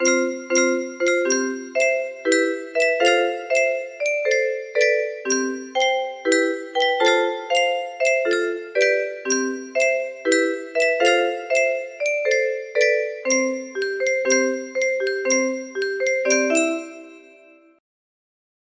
Own music music box melody